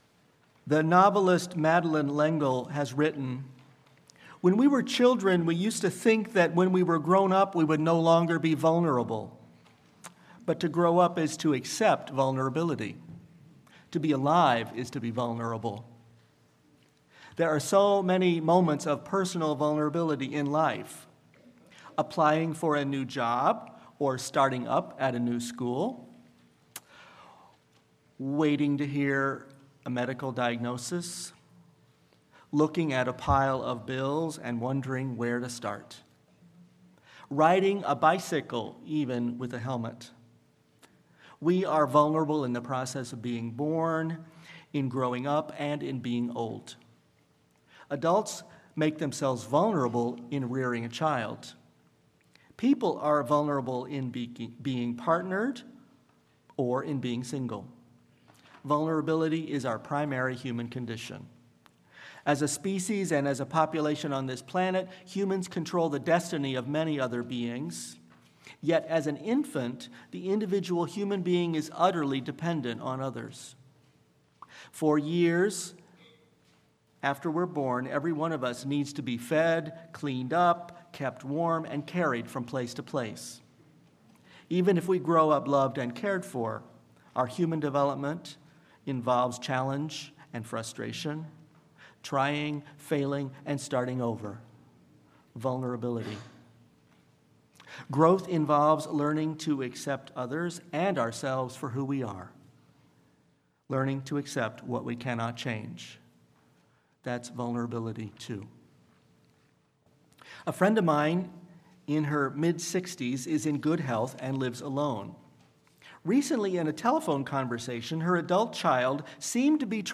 Sermon-Vulnerability-and-Solidarity.mp3